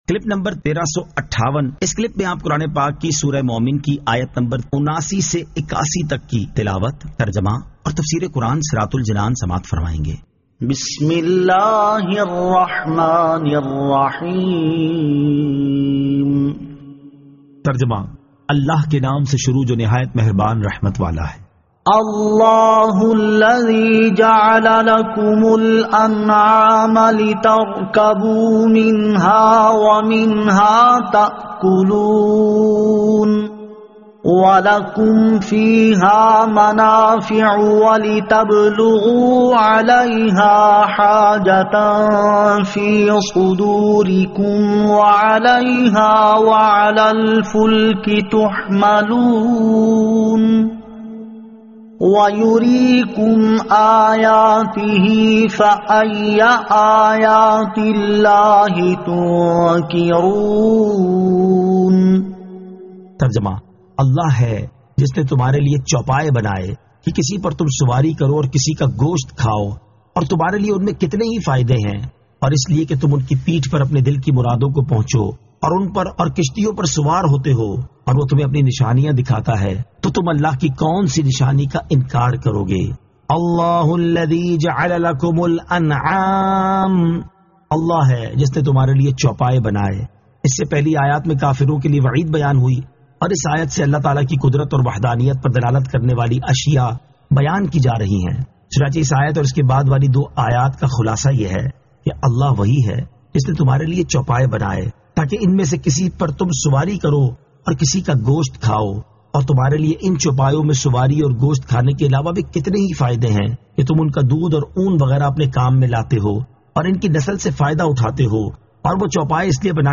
Surah Al-Mu'min 79 To 81 Tilawat , Tarjama , Tafseer